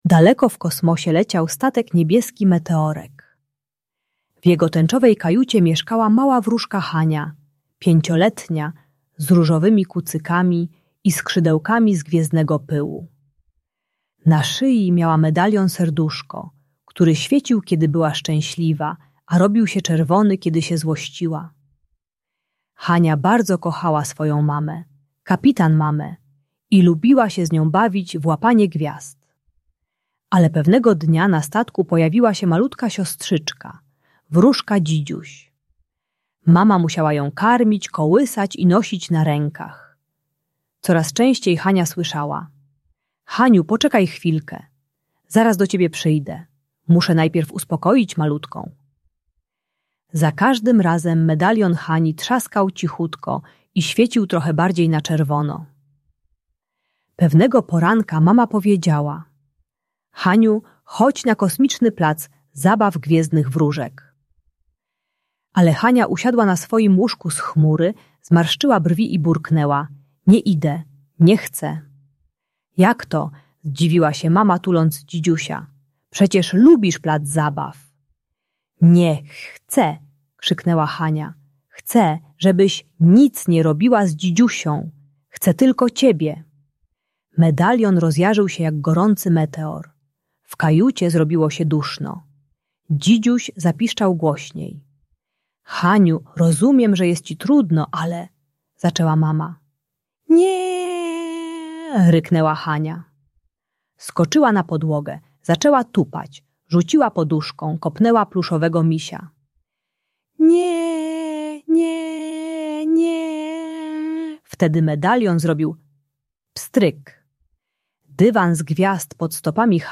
Niebieski Meteorek - Rodzeństwo | Audiobajka